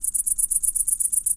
INSECT_Crickets_Segment_01_mono.wav